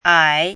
ǎi
ai3.mp3